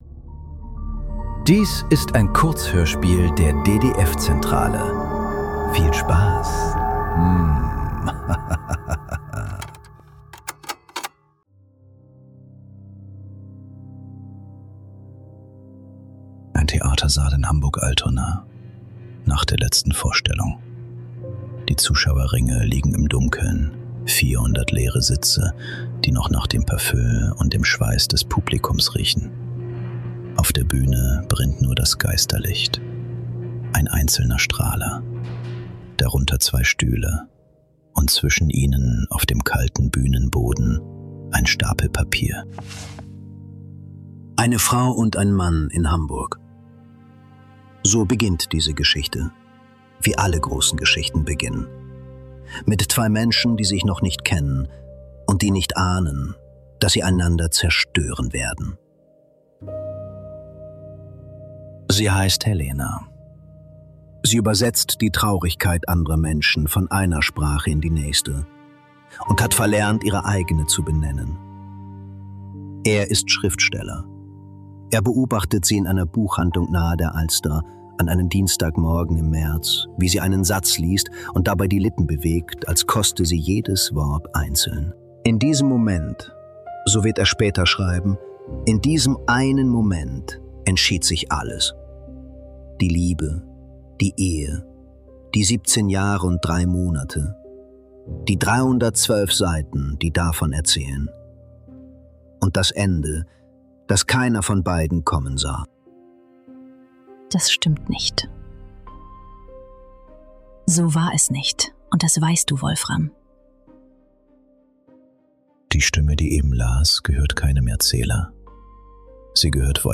Hanna - Ein Roman ~ Nachklang. Kurzhörspiele.